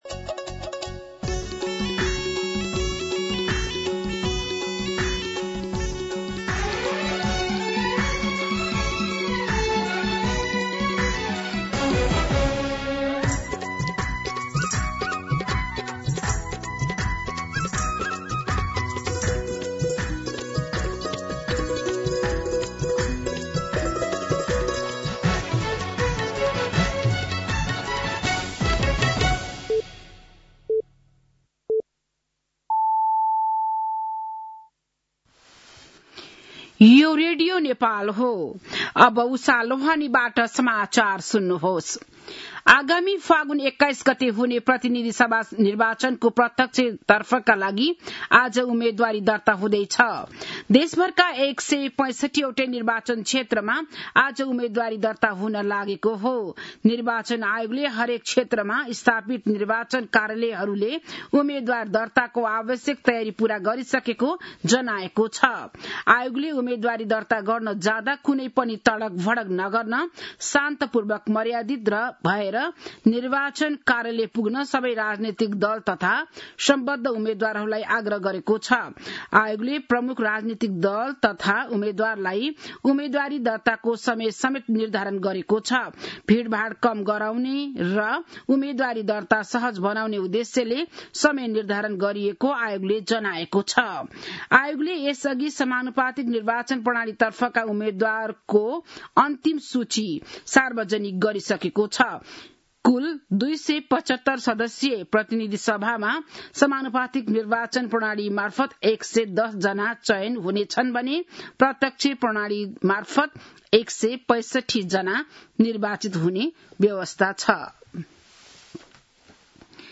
बिहान ११ बजेको नेपाली समाचार : ६ माघ , २०८२